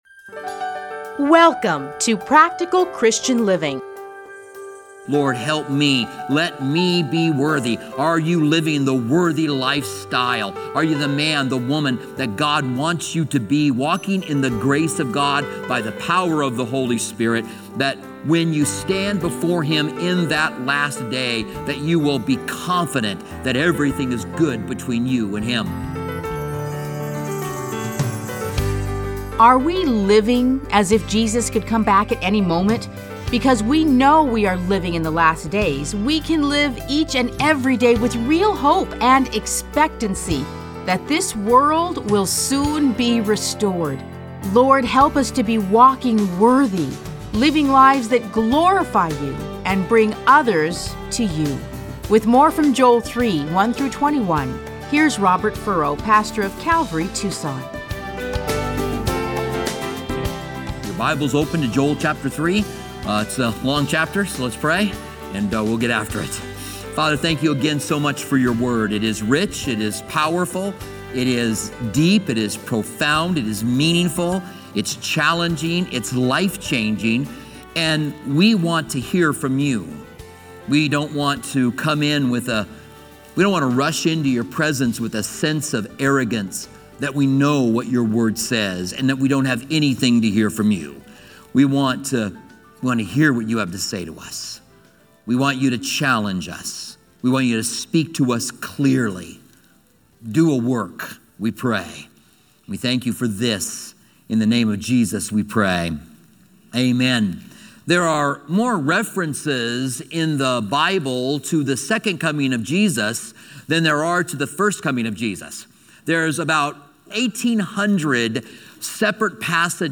Listen to a teaching from Joel 3:1-21.